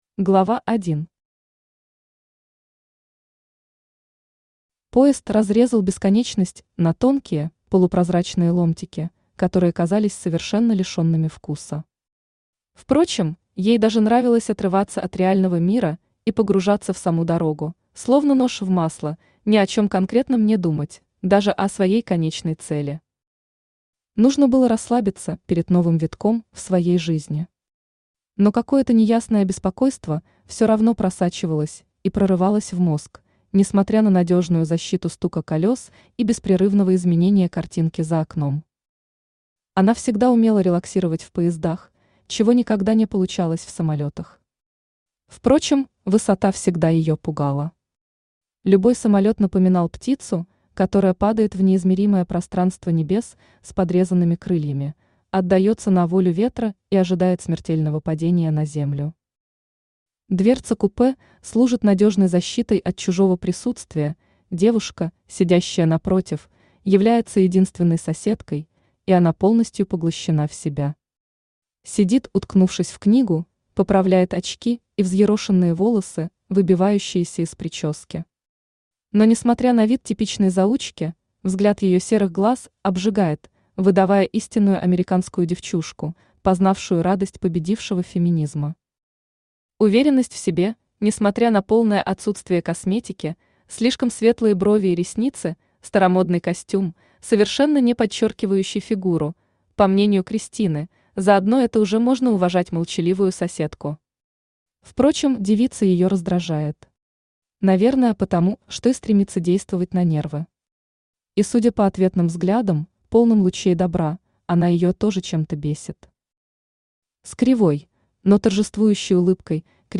Аудиокнига Иллюзия хрупкости | Библиотека аудиокниг
Aудиокнига Иллюзия хрупкости Автор Кристина Воронова Читает аудиокнигу Авточтец ЛитРес.